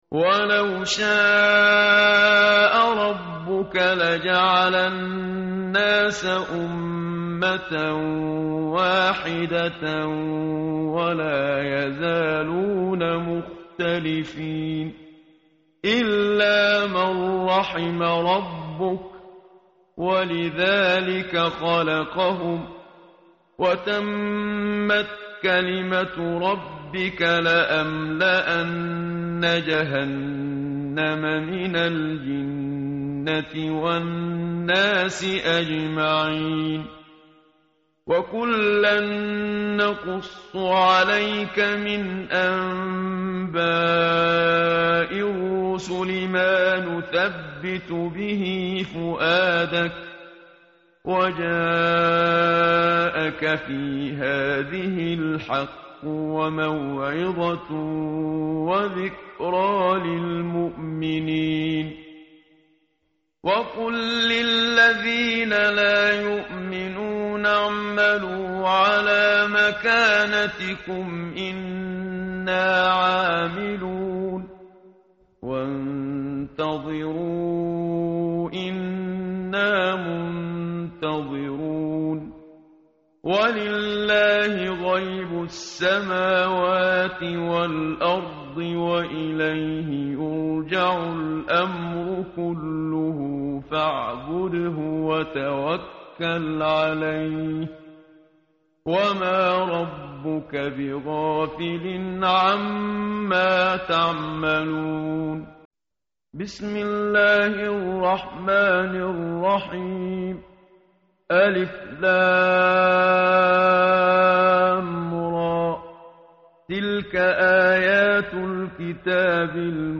tartil_menshavi_page_235.mp3